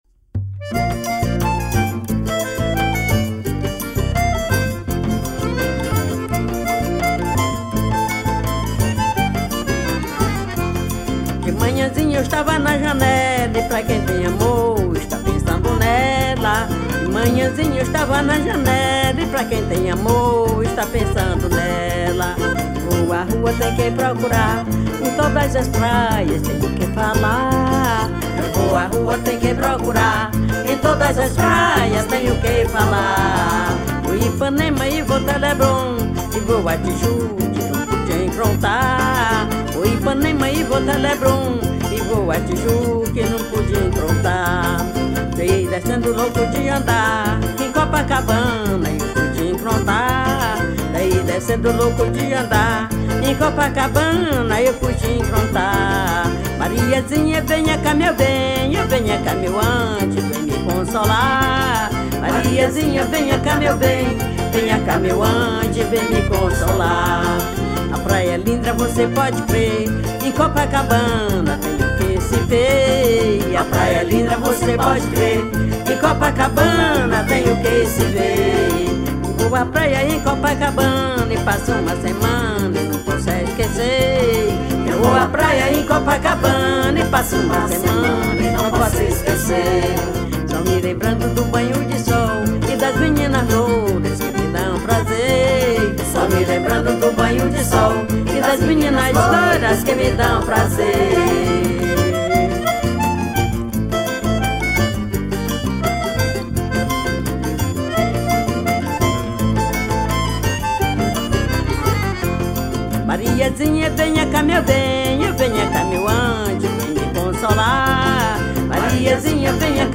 1246   02:51:00   Faixa:     Forró